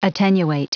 Prononciation du mot attenuate en anglais (fichier audio)
Prononciation du mot : attenuate